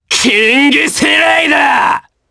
Ezekiel-Vox_Kingsraid_jp_b.wav